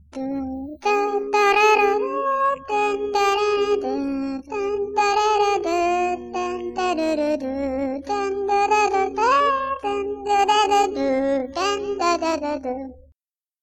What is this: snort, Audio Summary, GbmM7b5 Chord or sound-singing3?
sound-singing3